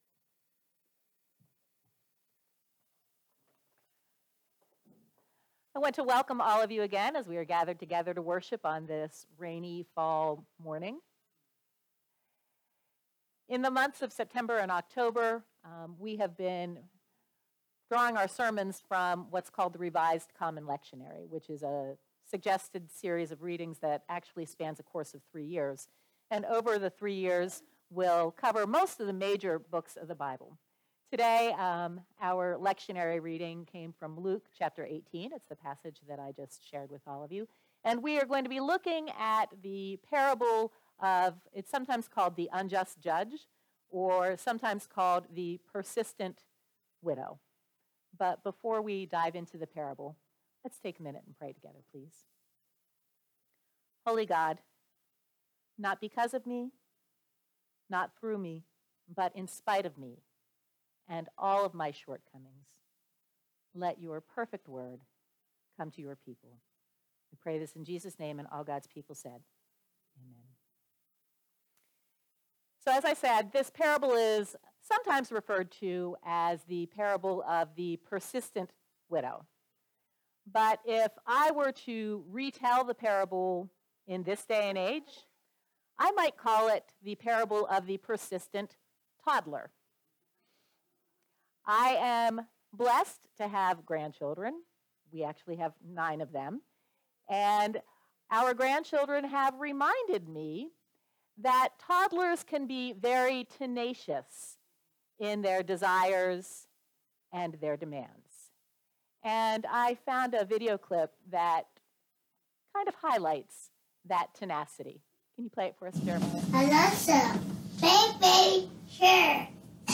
Morrisville United Methodist Church Sermons